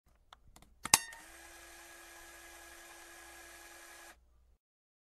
Tiếng cuộn phim đang chạy (Film roll)
Thể loại: Tiếng động
Description: Download hiệu ứng âm thanh, tải tiếng động tiếng cuộn phim đang chạy, tiếng cuộn phim của máy chiếu phim cổ điển thời xưa đang phát (Film roll) mp3 này miễn phí cho việc edit làm phim, ghép video, chỉnh sửa video.
tieng-cuon-phim-dang-chay-film-roll-www_tiengdong_com.mp3